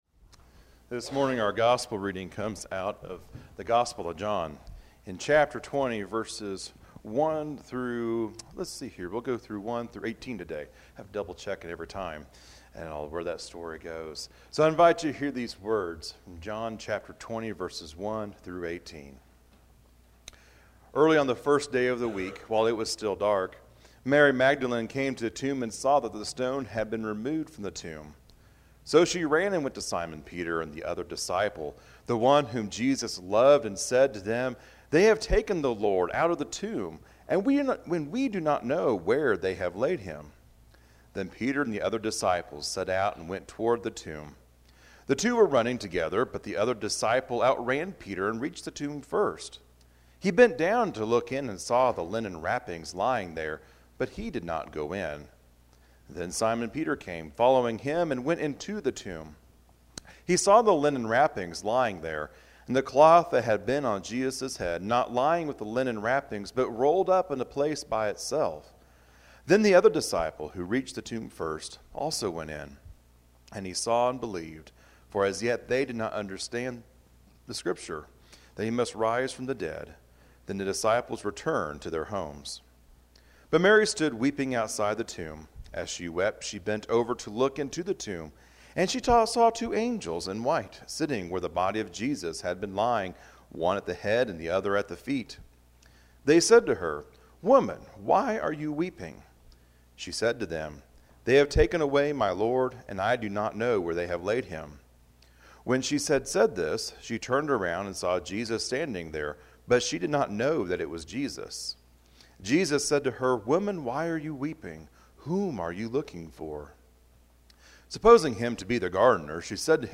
This recording is from McClave UMC.
Sunday-Sermon-April-21-Easter.mp3